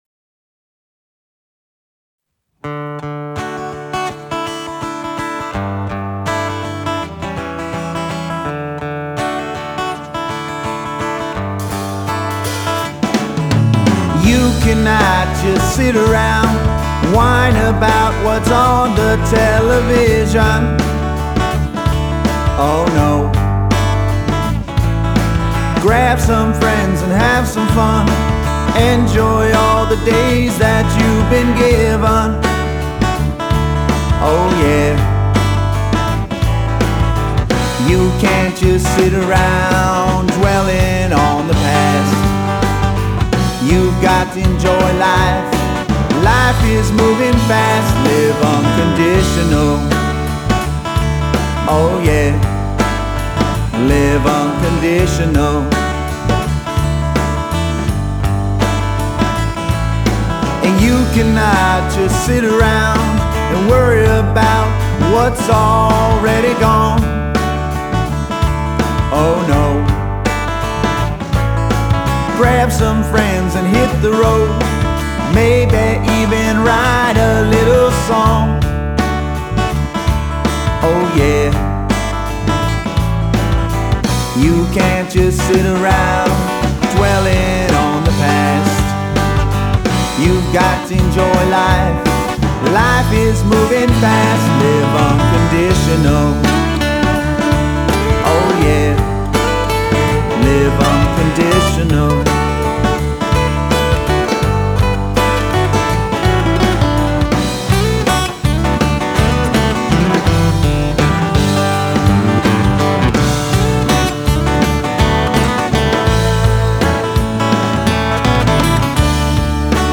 Genre: Rock, Funk Rock, Blues